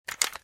Звуки отвертки